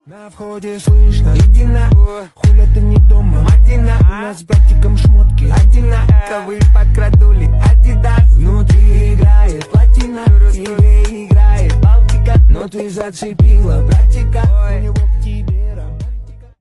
Внимание Ненормативная лексика!
Рэп и Хип Хоп